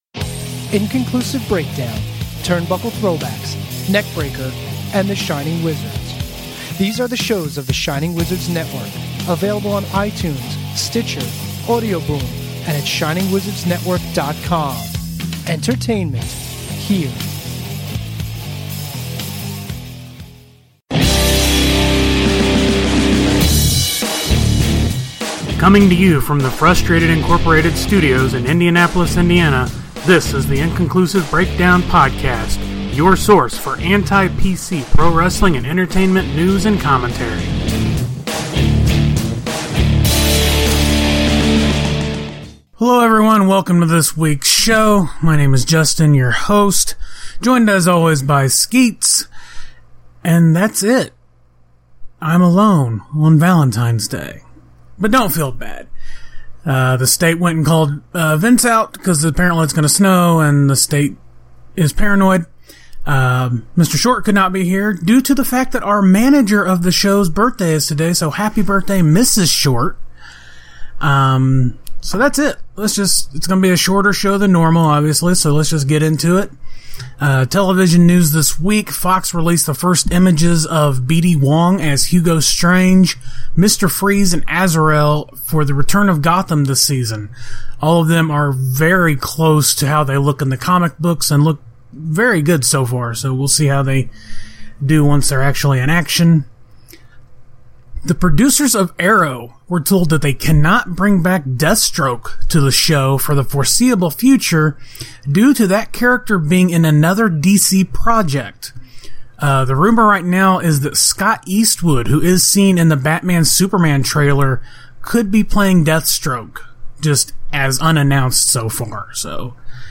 Solo show! (sorry) Top 10: Celebs we want to be our Valentine Fan Cast: Comic Book Couples Episode 96